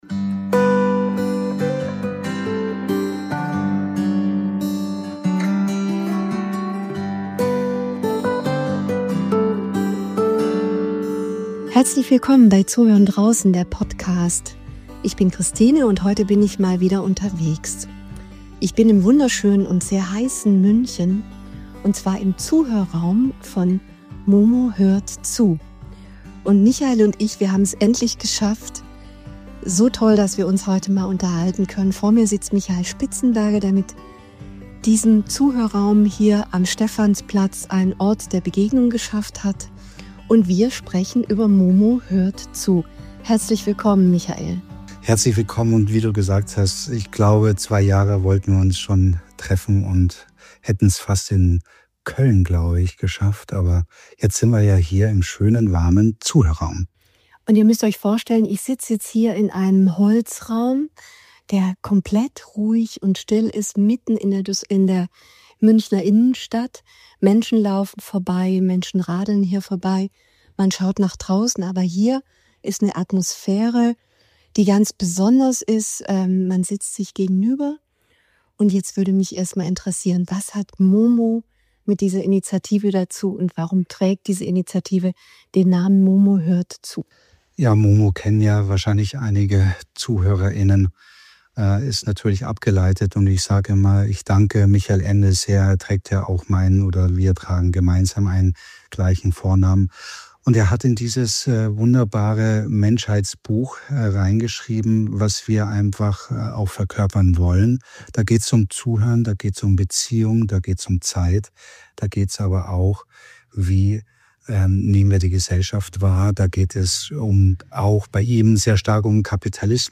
in München